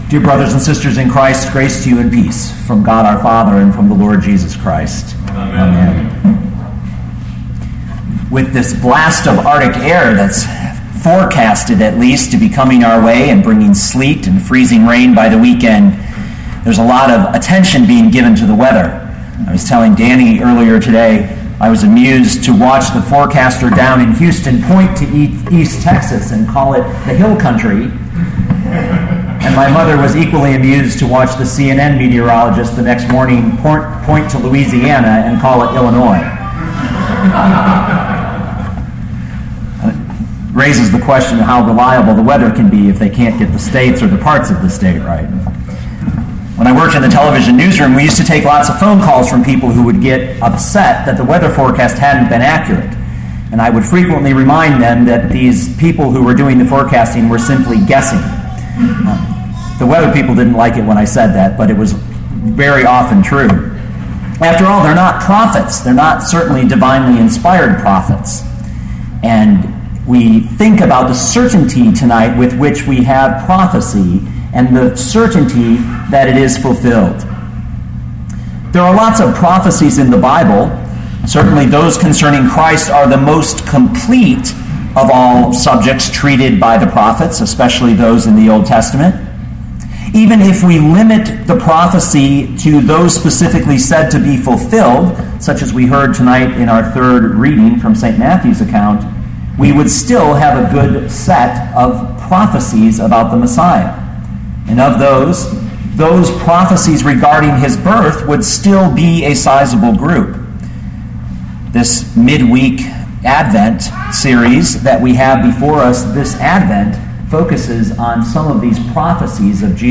Pilgrim Lutheran Church — Seed of a Virgin
Matthew 1:18-25 Listen to the sermon with the player below, or, download the audio.